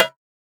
Loaded PERC.wav